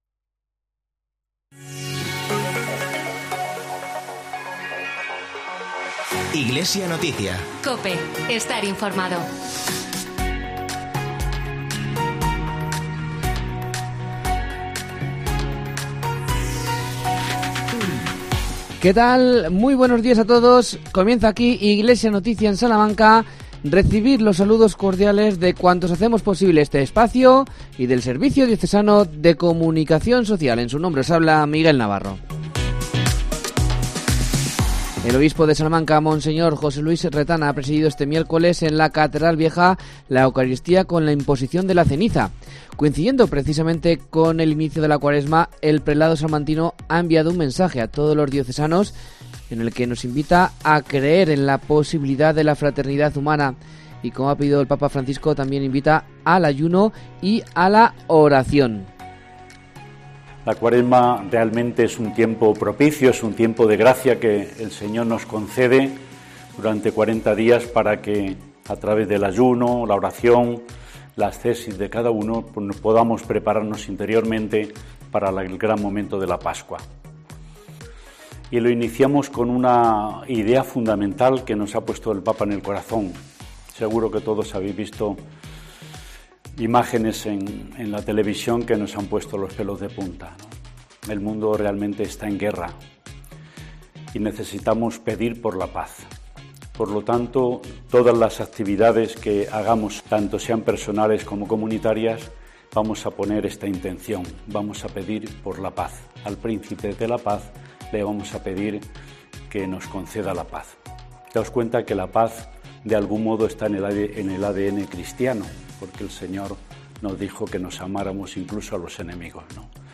AUDIO: Mensaje del obispo Monseñor Retana sobre la cuaresma.